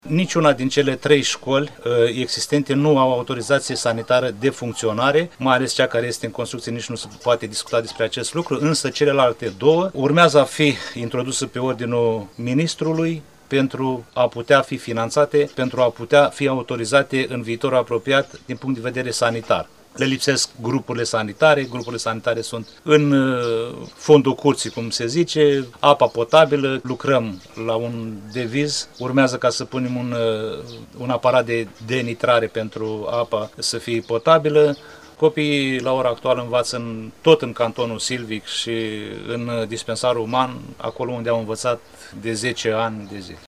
Hristache Sima, primar Cozmesti: